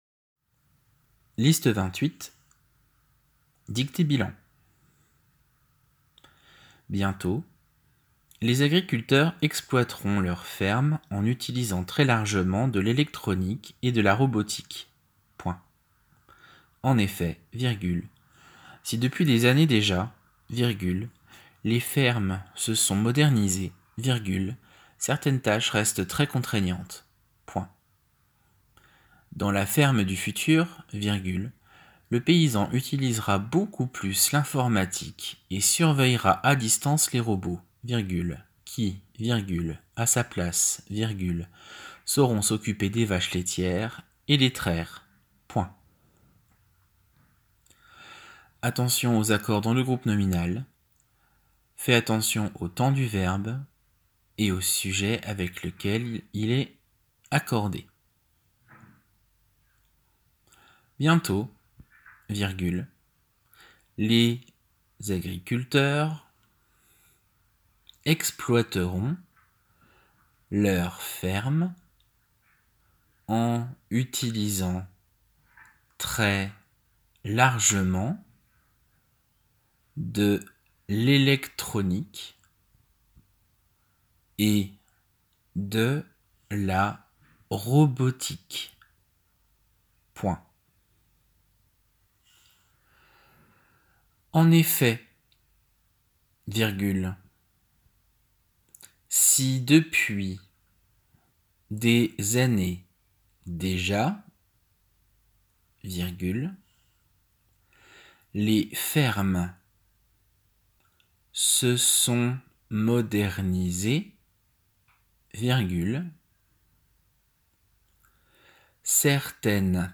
JEhkMUQ4fFe_Liste-28-dictée-bilan.m4a